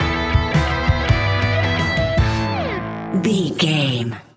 Ionian/Major
hard rock
blues rock
distortion